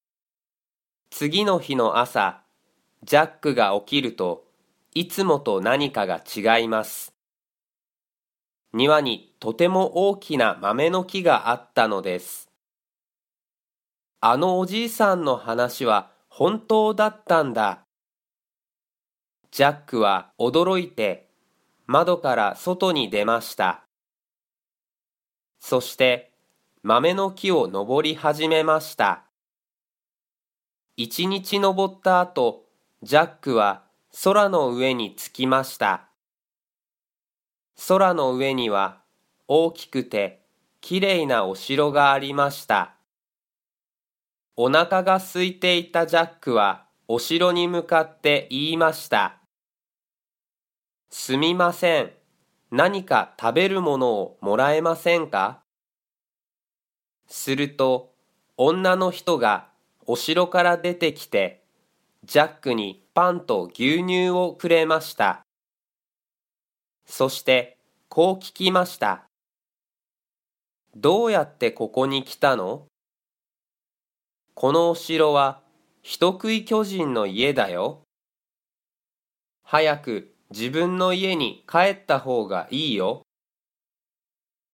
Japanese Graded Readers: Fairy Tales and Short Stories with Read-aloud Method
Natural Speed